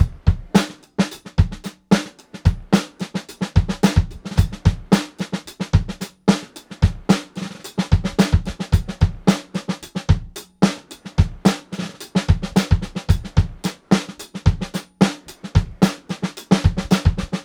• 110 Bpm Drum Groove E Key.wav
Free drum groove - kick tuned to the E note.
110-bpm-drum-groove-e-key-KwC.wav